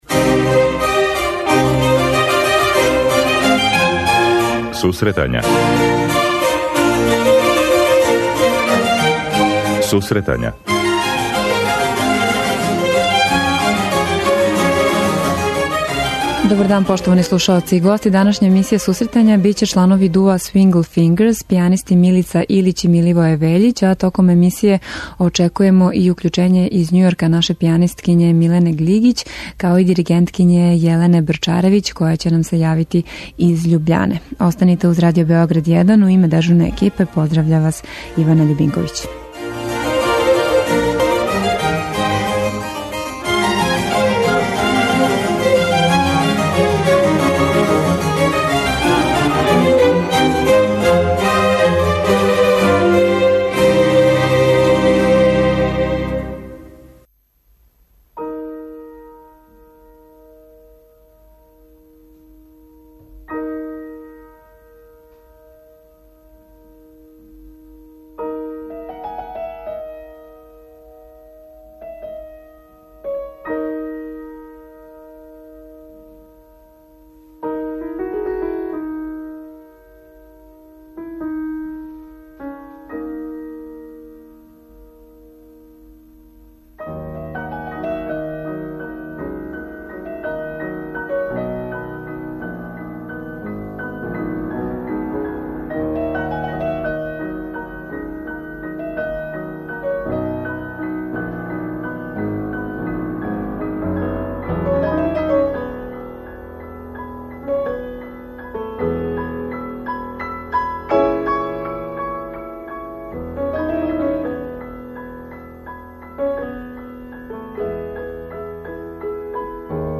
преузми : 26.38 MB Сусретања Autor: Музичка редакција Емисија за оне који воле уметничку музику.